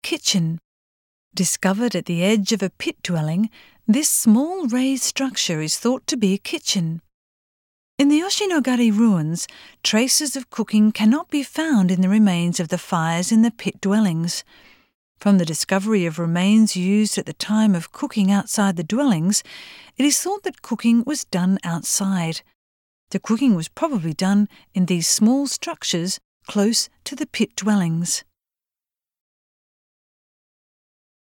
Voice guide